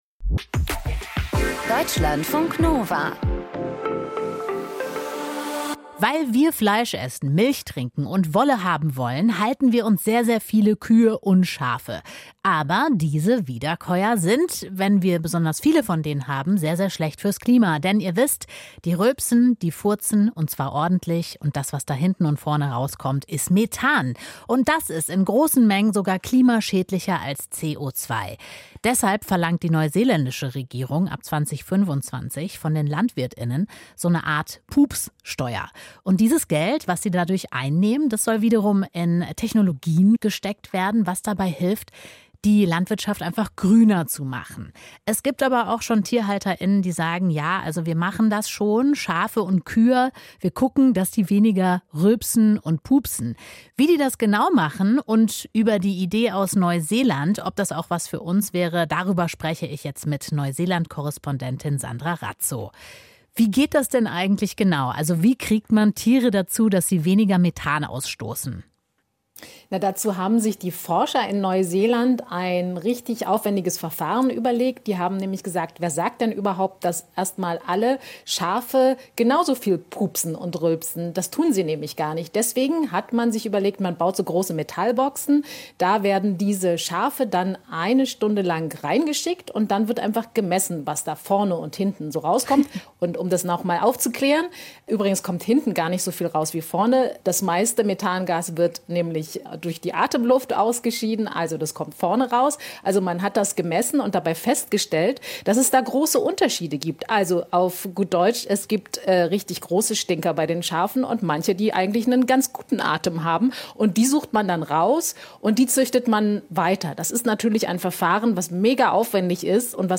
Rezension - Eat, Poop, Die. Wie Tiere unsere Welt verändern